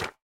resin_brick_place5.ogg